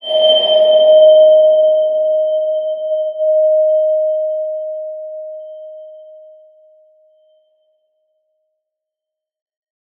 X_BasicBells-D#3-mf.wav